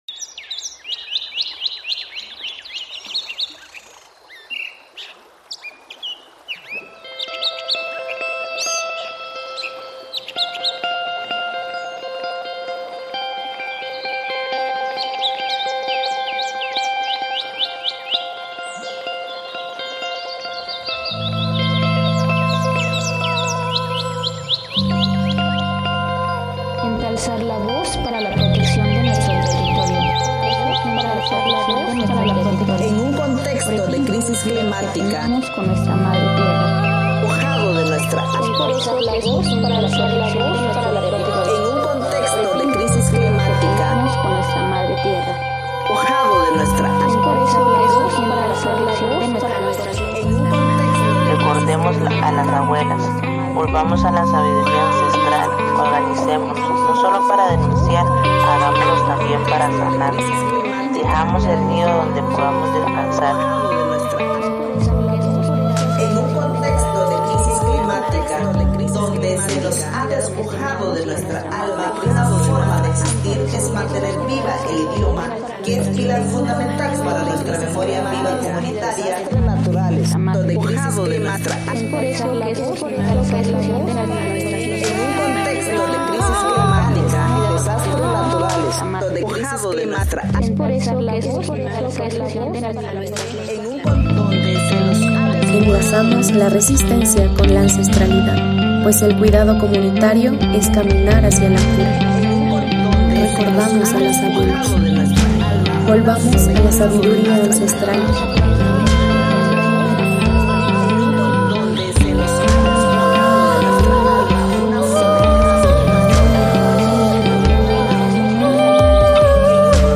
Paisaje Sonoro Milpamérica